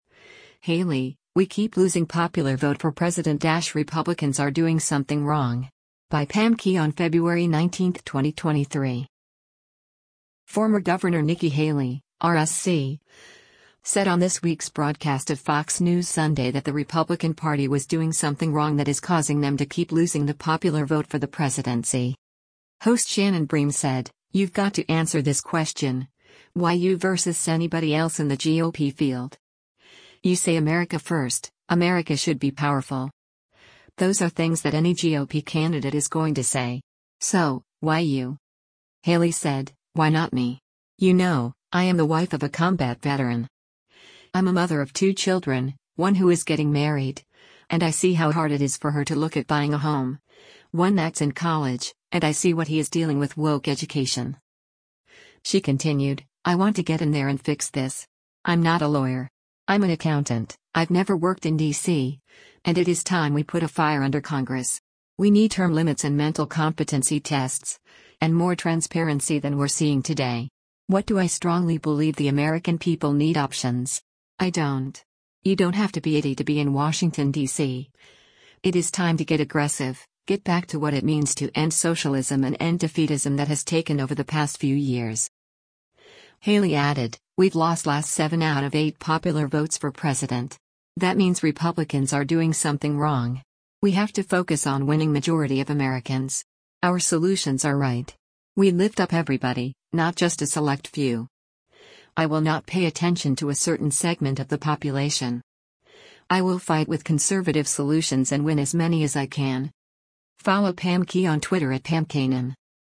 Former Gov. Nikki Haley (R-SC) said on this week’s broadcast of “Fox News Sunday” that the Republican Party was “doing something wrong” that is causing them to keep losing the popular vote for the presidency.